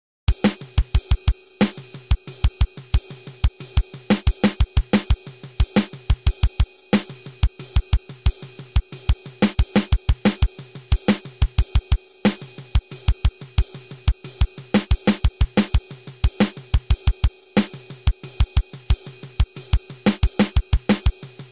This is a substantial groove, a meat sandwich sort of groove. It began as a bass-drum workout — playing 4 distinct strokes on the kick is a challenge with one foot — and evolved into a snare-dynamics workout — check the accents in the snare pattern.
The samples below use a standard quarter-note pulse on the ride, but this pattern sounds phenomenal with an ostinato such as 1 +a or, even cooler, 1e a for an upbeat push.
meatsandwich.mp3